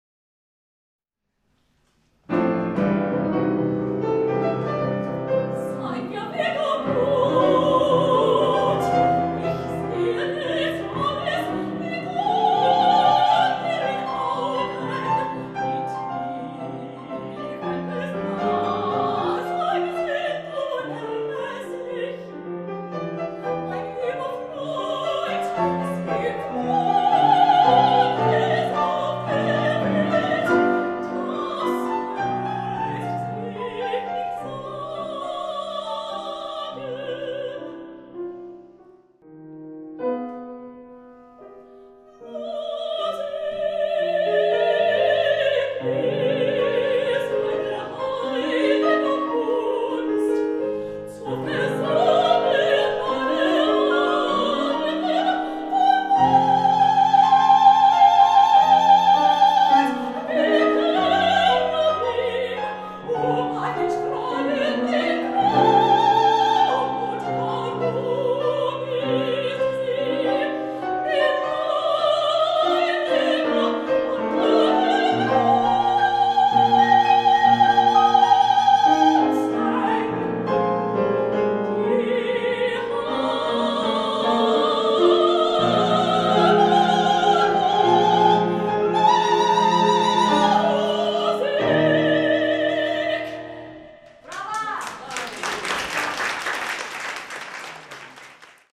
MP3-Demos: Ausschnitte und Zusammenschnitte vom Abschlusskonzert V. Meisterkurs 2016 ... ... per Download-mp3-Link (einfach jeweils auf die vorangestellte Zahl/Track klicken)
Mezzosopran